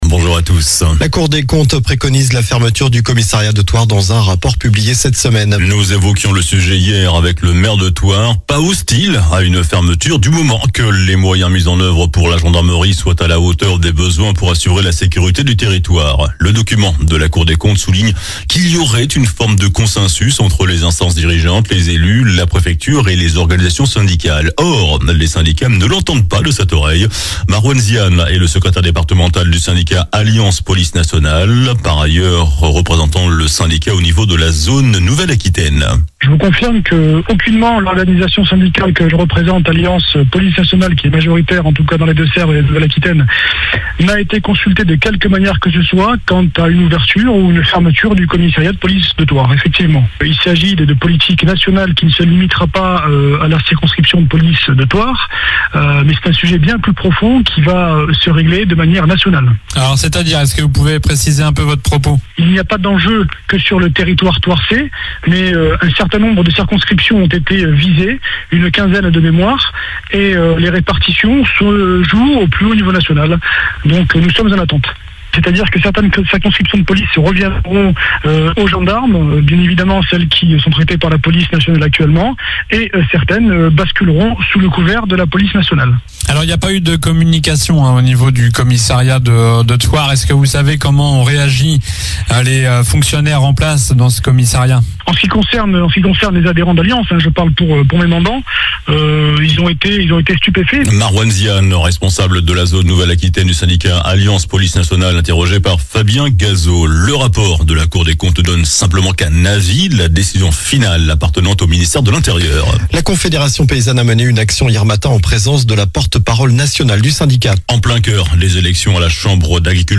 JOURNAL DU SAMEDI 18 JANVIER